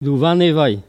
Patois
Catégorie Locution